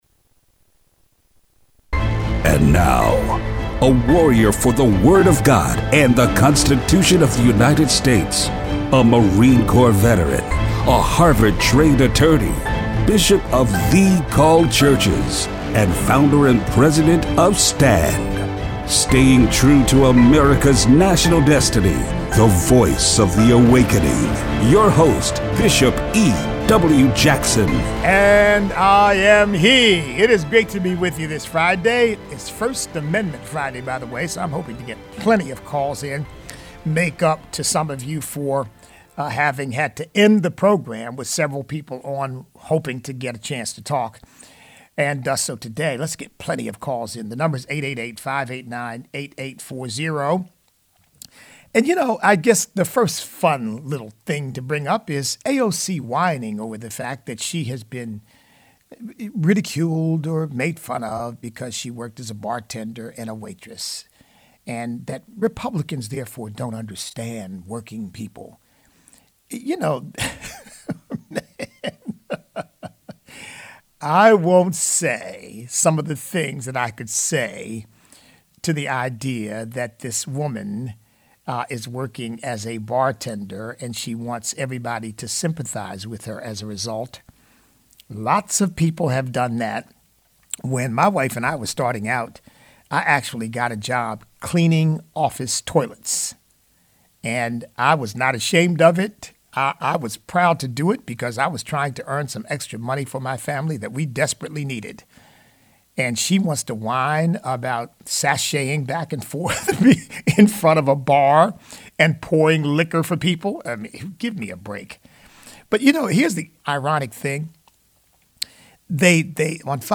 Show Notes Open phone lines.